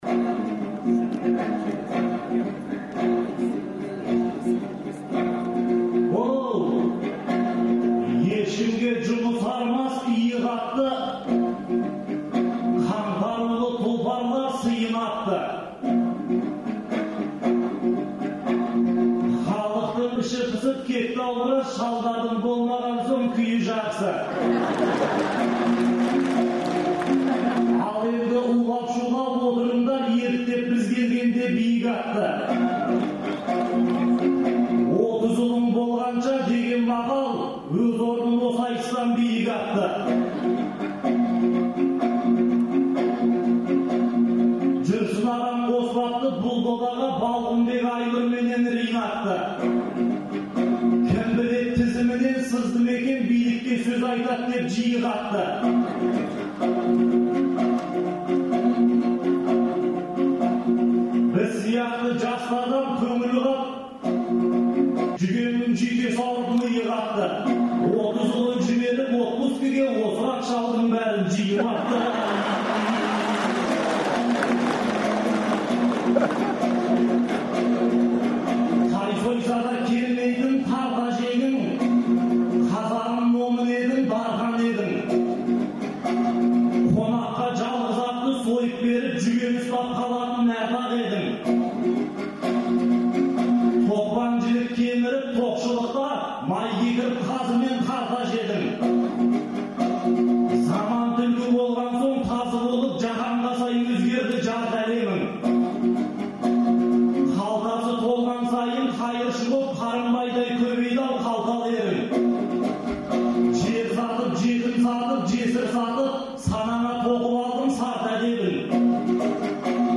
Маусымның 2-сі күні Республика сарайында өткен «Ретро айтысқа» жас ақындар да шақырылды.